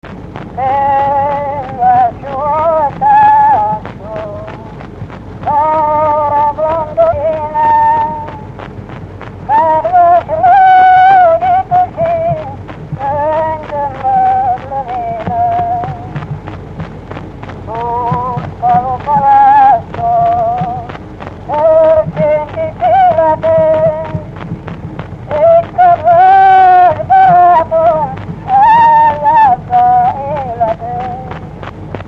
Erdély - Udvarhely vm. - Zetelaka
Stílus: 9. Emelkedő nagyambitusú dallamok